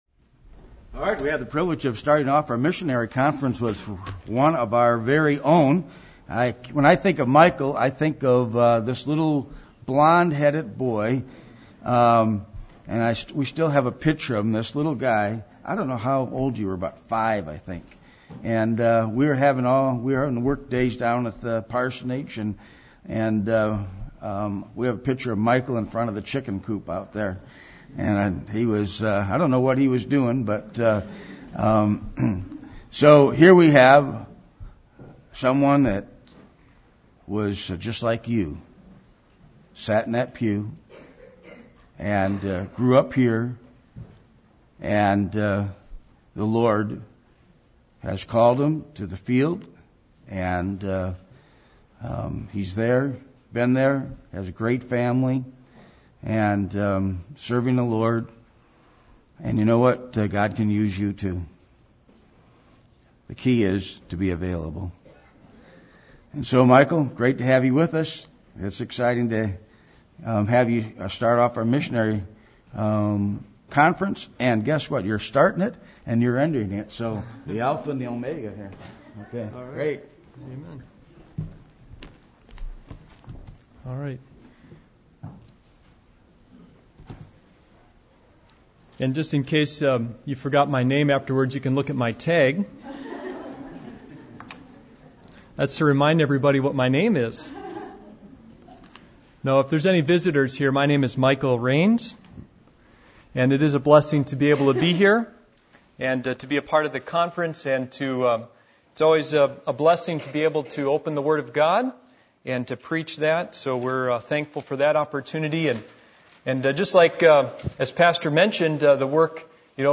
Passage: Matthew 9:35-10:1 Service Type: Missions Conference